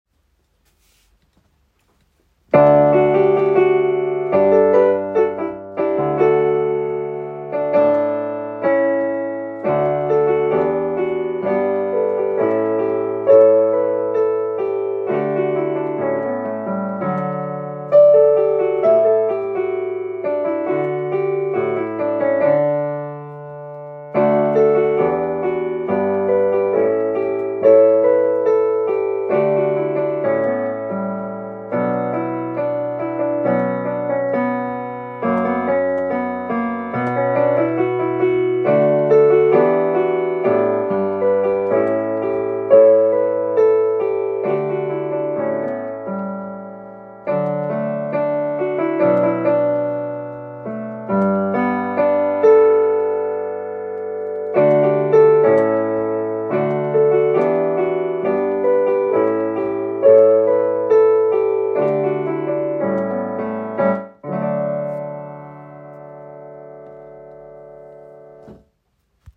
O Great Bridge Base is in the key of D major, consisting of eight 4-bar verses. Its structure is set as a verse with a chorus afterwards – an 'A-B-C-B-D-B-E-B' pattern.